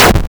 shotgun.wav